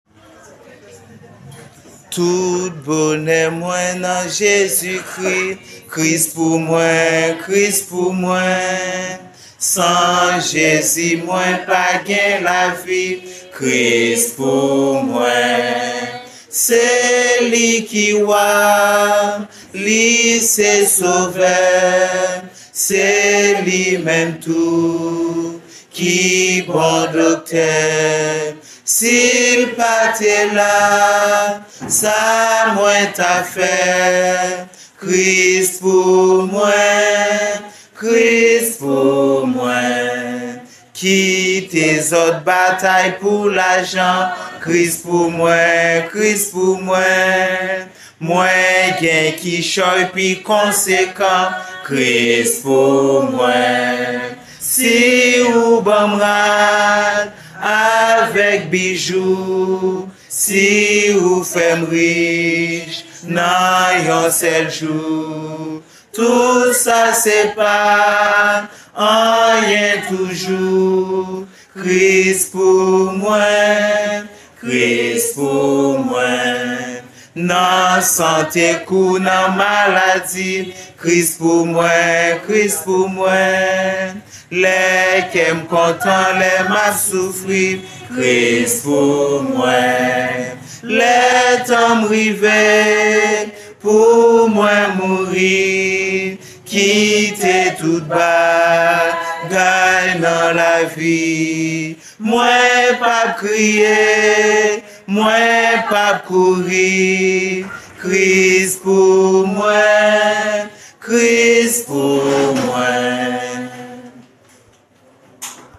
Kreyol Song – Tout bone mwen nan Jezikri (Christ for Me)